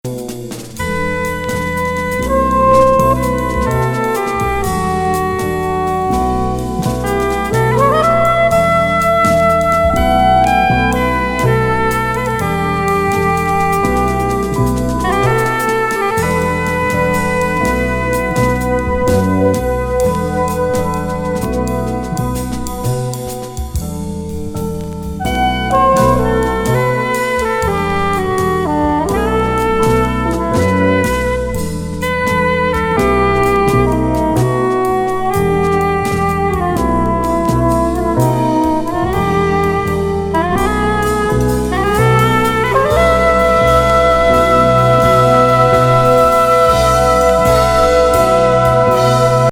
コンテンポラリーなセッションにフワフワ・ベースが気持ち良い!!